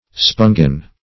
Search Result for " spongin" : The Collaborative International Dictionary of English v.0.48: Spongin \Spon"gin\ (sp[u^]n"j[i^]n), n. (Physiol. Chem.) The chemical basis of sponge tissue, a nitrogenous, hornlike substance which on decomposition with sulphuric acid yields leucin and glycocoll.